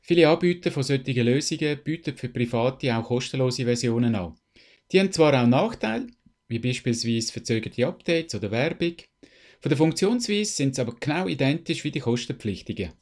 Dieses Interview gibt es auch auf Hochdeutsch!!